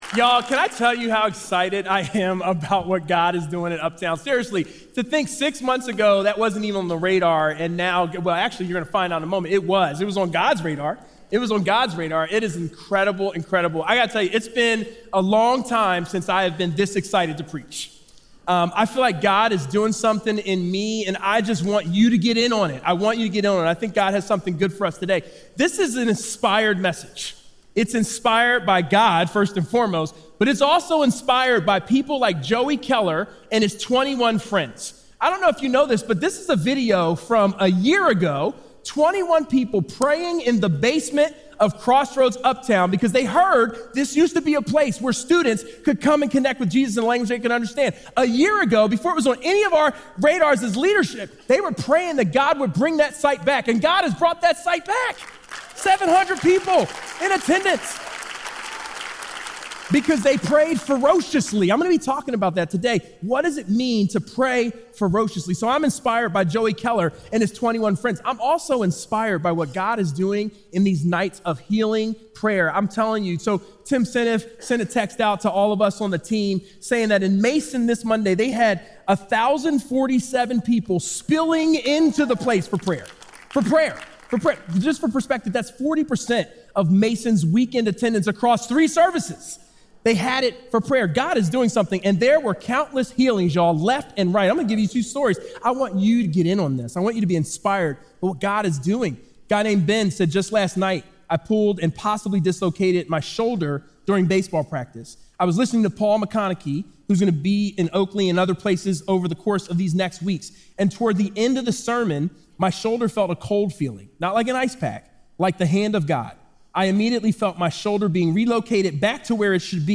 Recorded live at Crossroads Church in Cincinnati, Ohio.